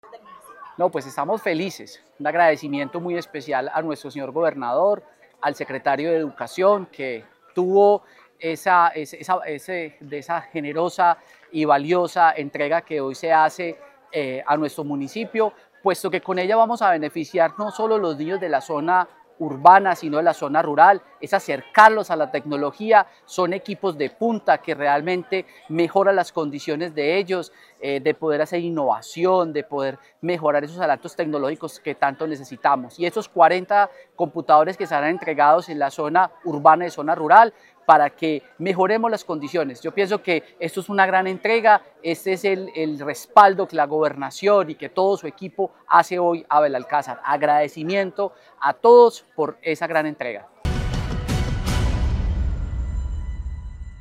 Alcalde de Belalcázar, Fabio Andrés Ramírez Giraldo.